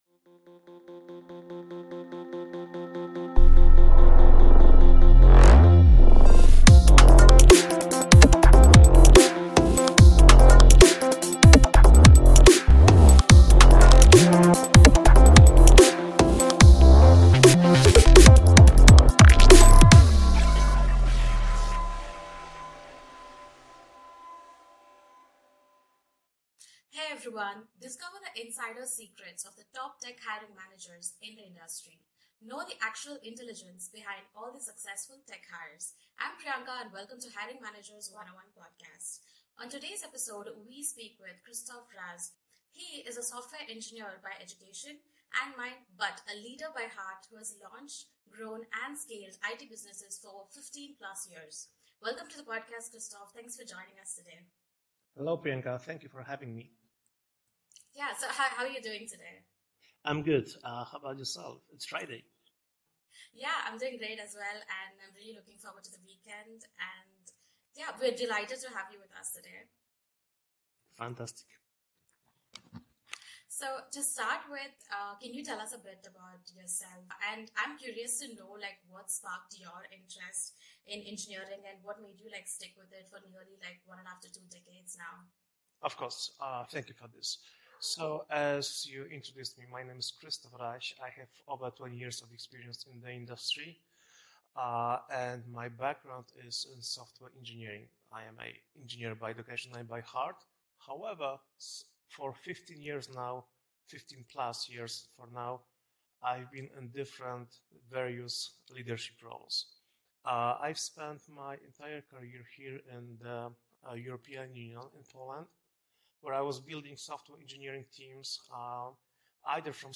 In this episode, the discussions revolve around hiring metrics that companies should focus on, handling diverse teams, strategies for effective tech hiring, and much more. Listen to the unfolding of a great conversation on technical hiring.